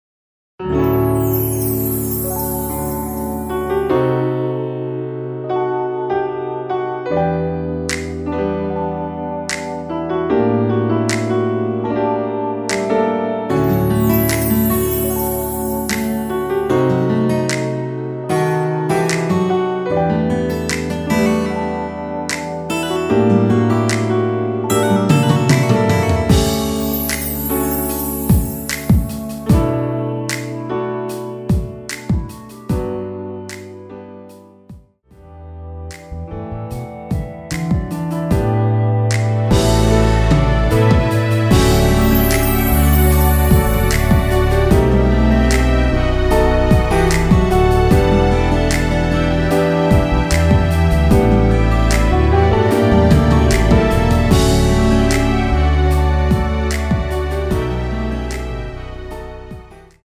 원키에서(-2)내린 멜로디 포함된 MR입니다.
D
음정과 박자 맞추기가 쉬워서 노래방 처럼 노래 부분에 가이드 멜로디가 포함된걸
앞부분30초, 뒷부분30초씩 편집해서 올려 드리고 있습니다.
중간에 음이 끈어지고 다시 나오는 이유는